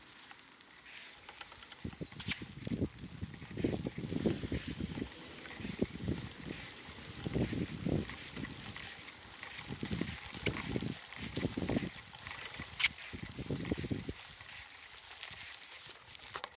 基础建设制动 " 制动器混凝土高速OS
描述：在混凝土上的山地自行车制动
标签： 混凝土 山地自行车
声道立体声